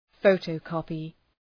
Προφορά
{‘fəʋtəʋ,kɒpı}